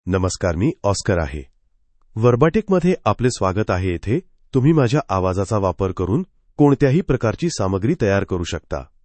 Oscar — Male Marathi AI voice
Voice sample
Listen to Oscar's male Marathi voice.
Male
Oscar delivers clear pronunciation with authentic India Marathi intonation, making your content sound professionally produced.